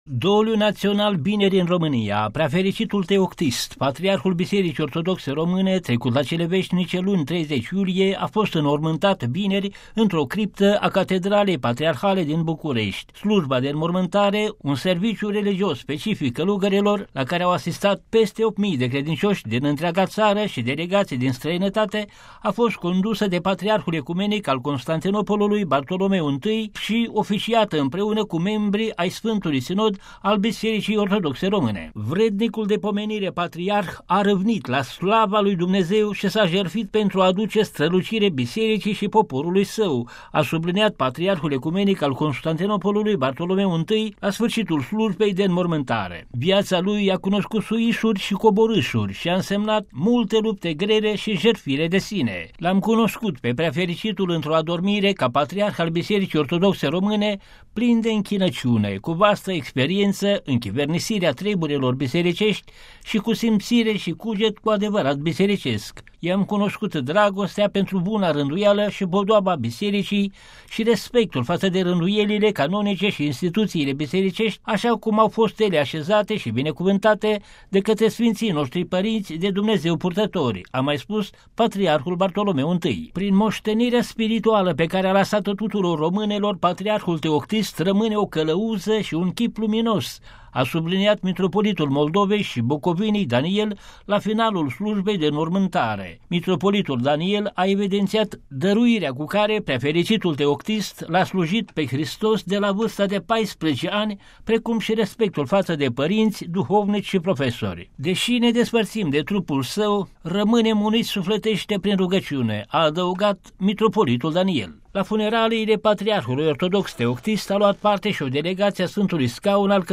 Ins- secvenţe muzicale din Missa “Requiem” de Giuseppe Verdi.